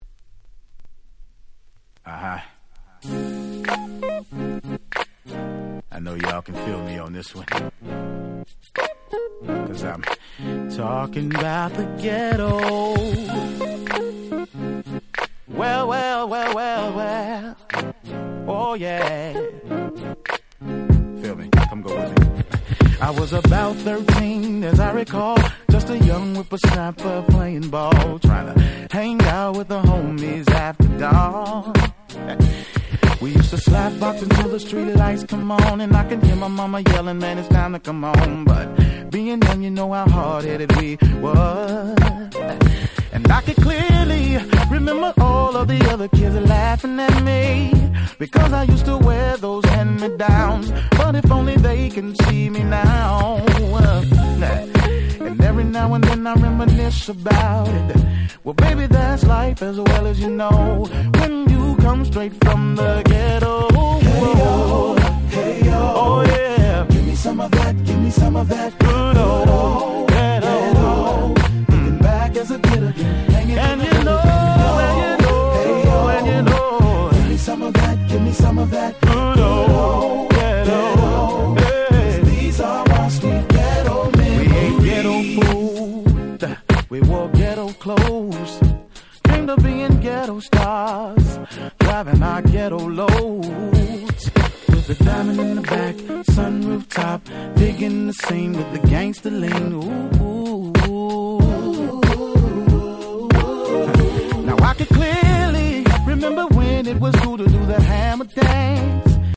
03' Very Nice R&B EP !!